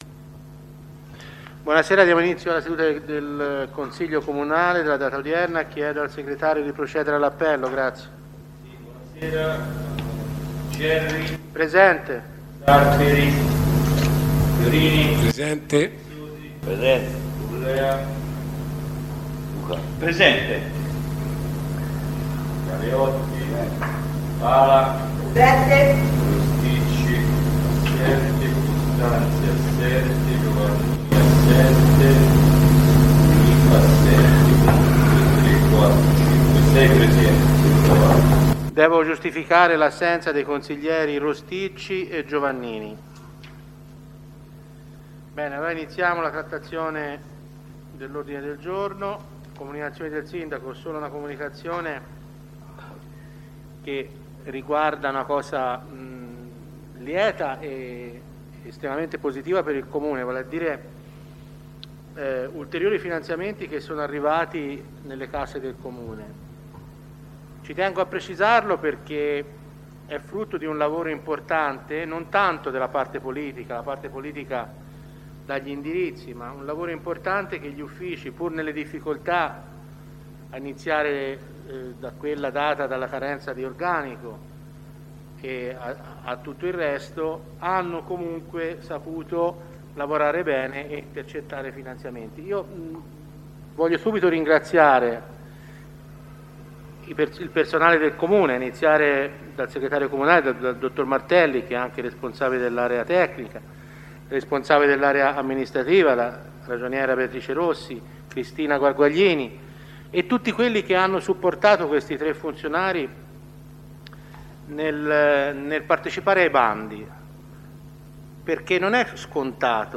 Consiglio Comunale del 29/07/2022 - Comune di Montecatini Val di Cecina
download di registrazione audio seduta